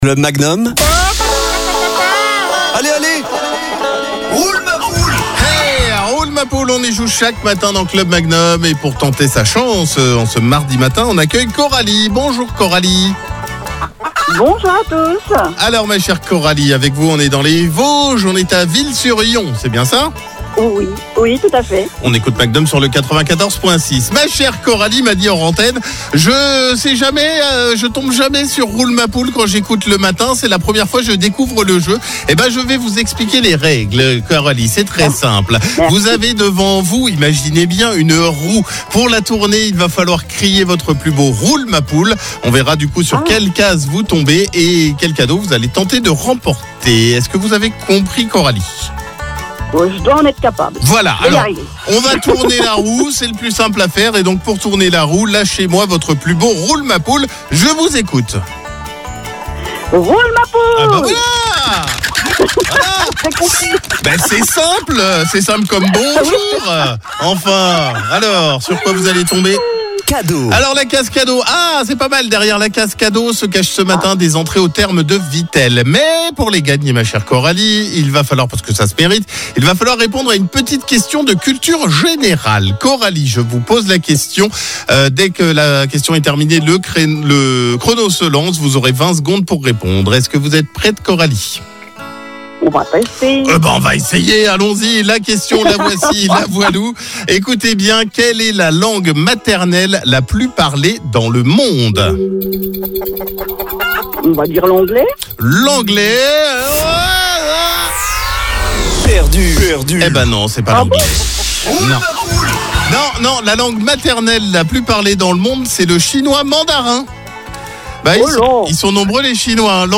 Tournez la roue en criant « Roule ma poule » , plus vous criez fort, plus la roue va tourner.